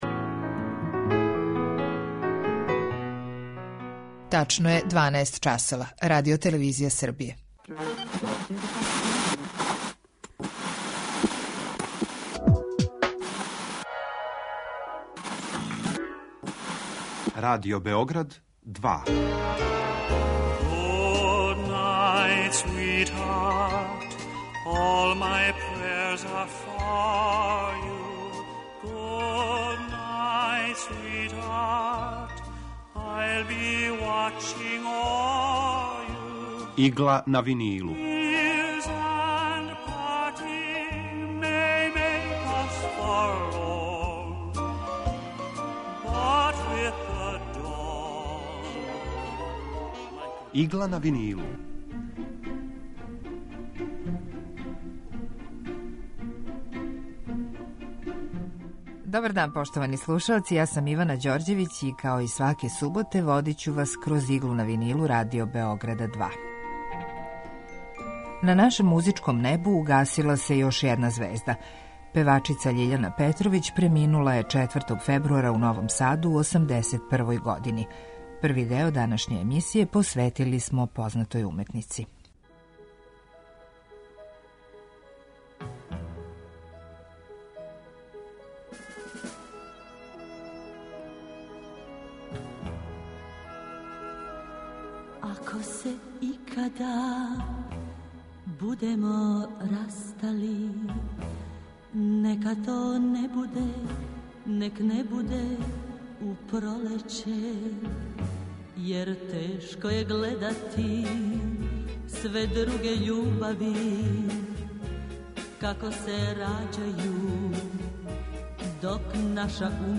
Евергрин музика